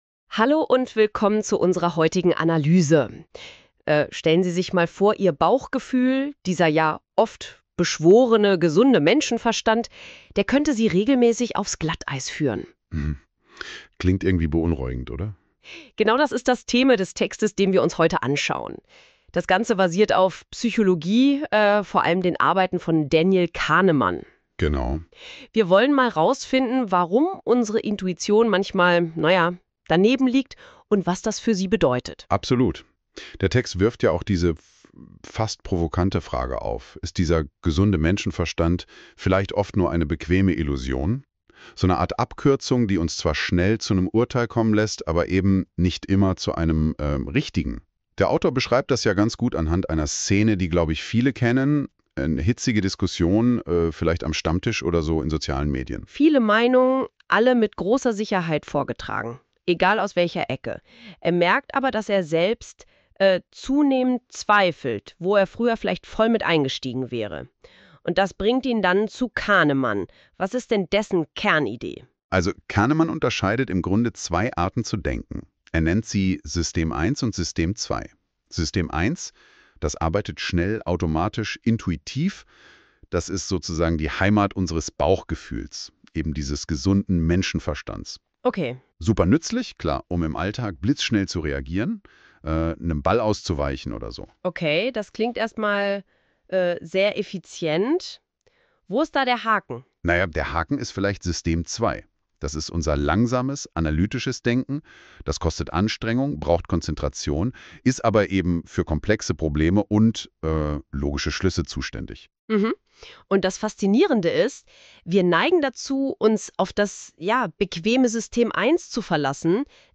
Zwei Podcasts zum Artikel (KI-erzeugt: NotebookLM)